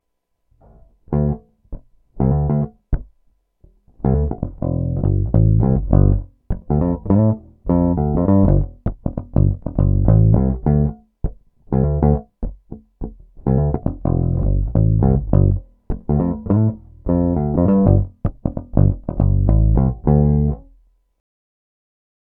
Clip 8 – SF – Neck – Pick – Tone Down
916-SF-Neck-Pick-Tone-DOWN.m4a